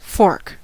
fork: Wikimedia Commons US English Pronunciations
En-us-fork.WAV